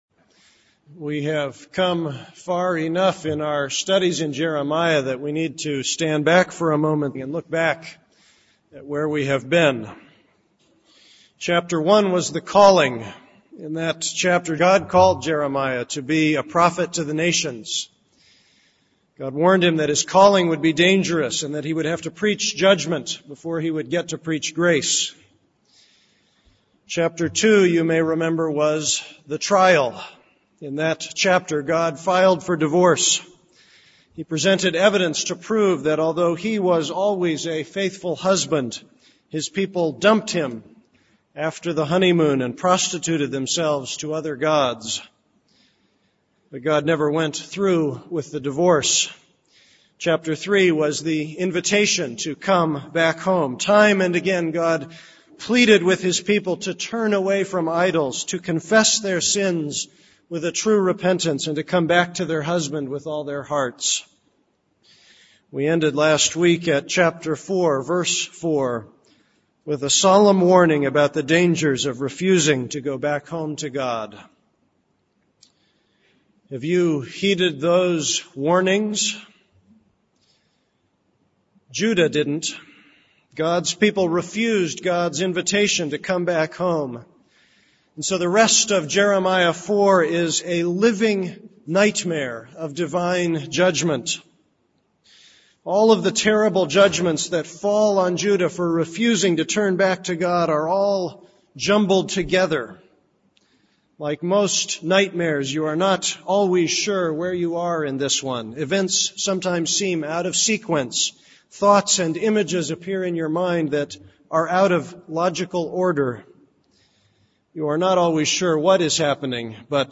This is a sermon on Jeremiah 4:5-31.